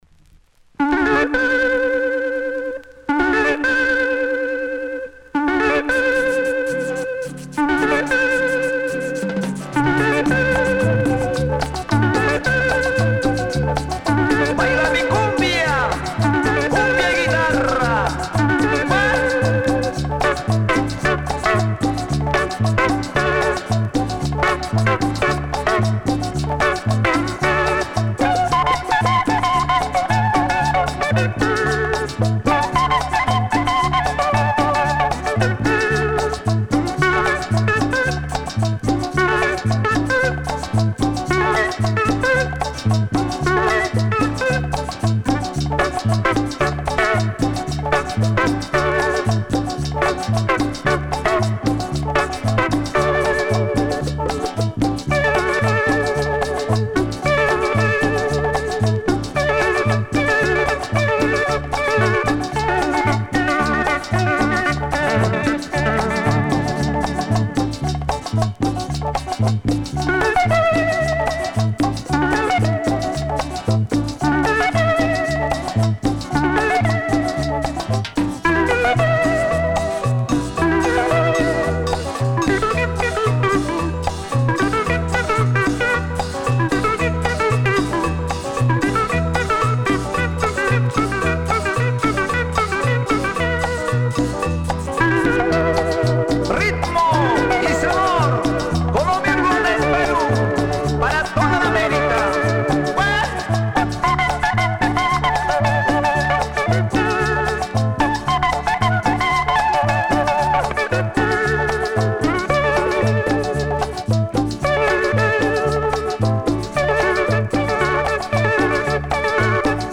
Published September 26, 2010 Cumbia 14 Comments